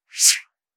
5. email sms sent